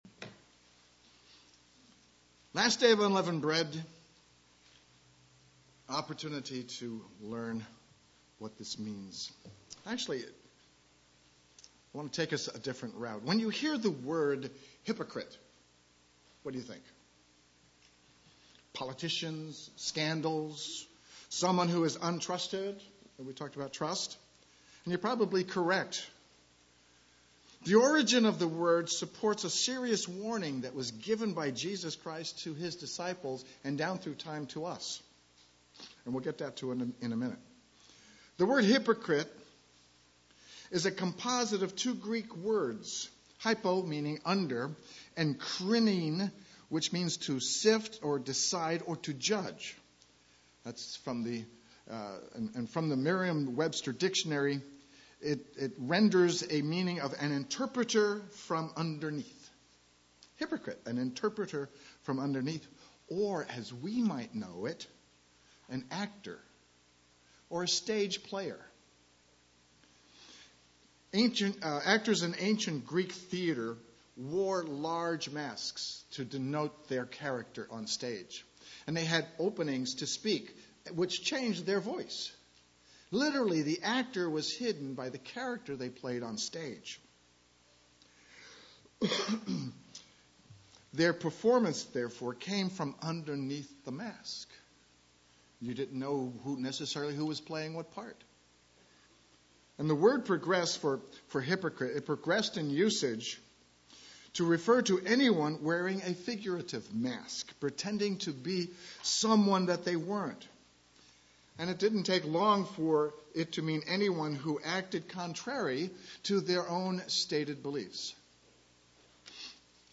Sermons
Given in Eureka, CA San Jose, CA